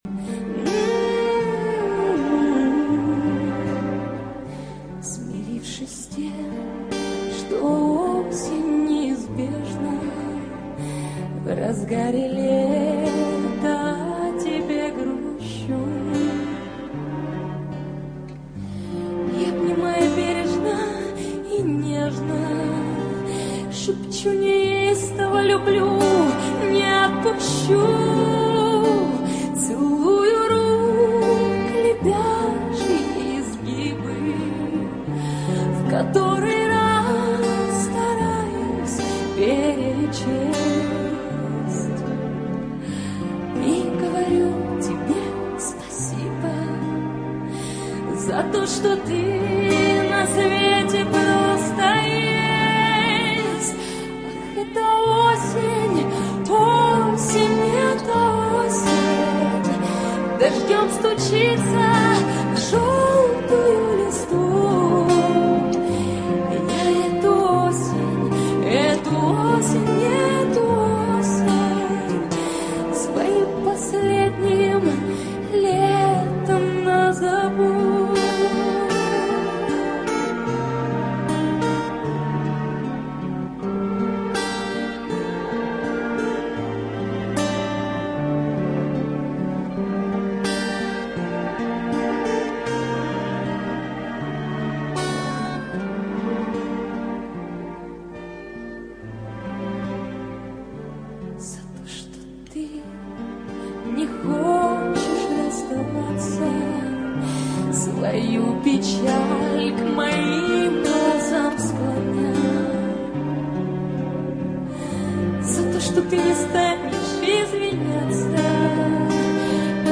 А это романс с голосом: